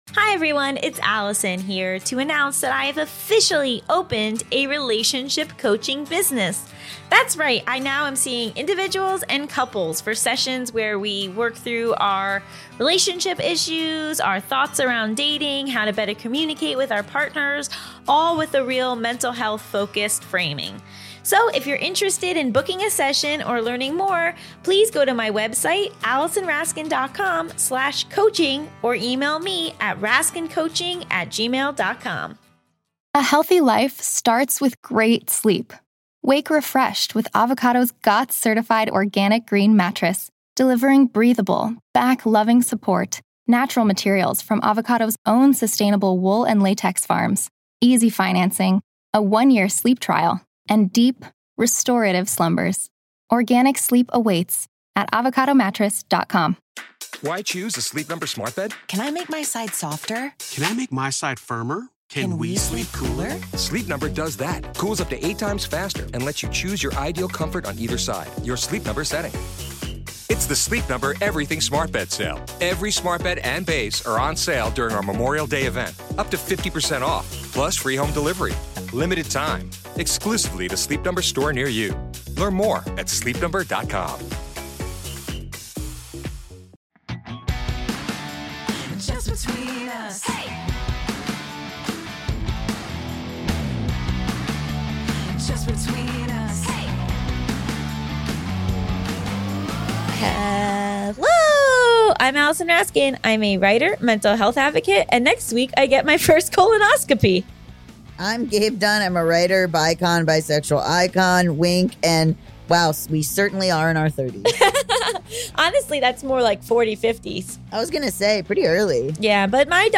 Mental Health, Education, Sexuality, Comedy, Self-improvement, Relationships, Comedy Interviews, Society & Culture, Personal Journals, Health & Fitness